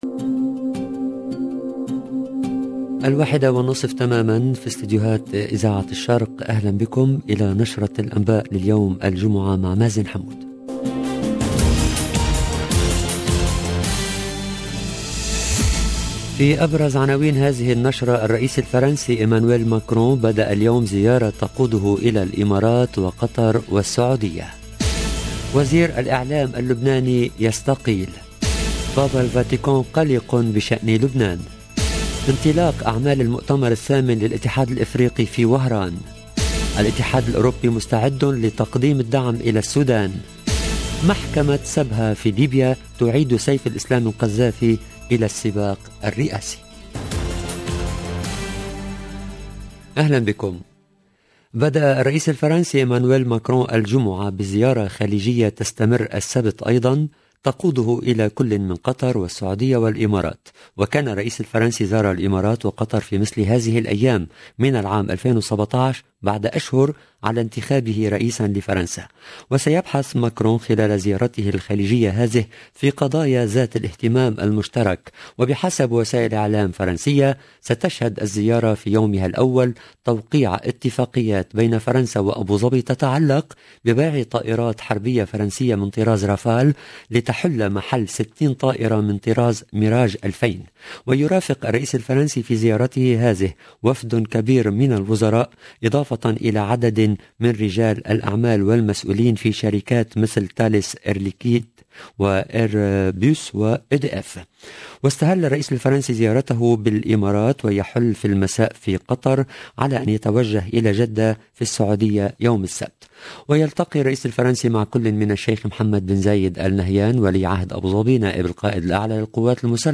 LE JOURNAL EN LANGUE ARABE DE LA MI-JOURNEE DU 3/12/2021